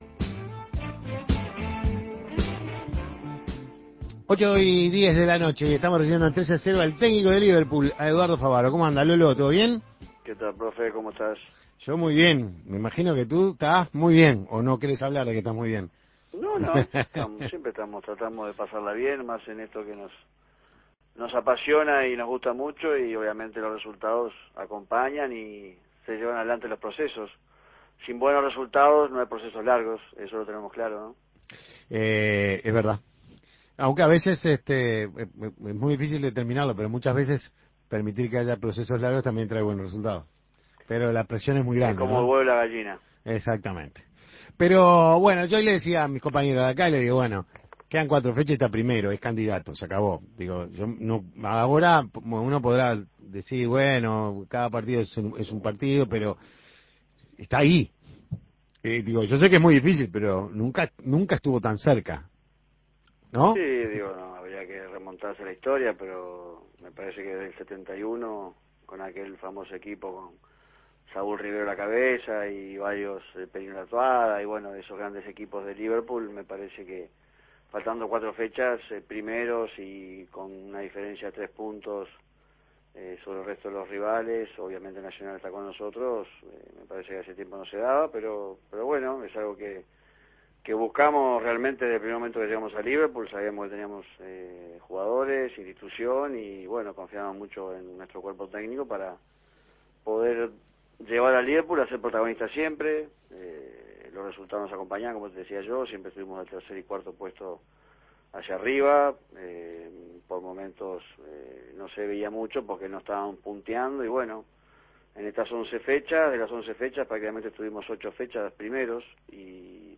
habló con 13 a 0 de este particular momento que atraviesan "los de la Cuchilla". Escuche la entrevista.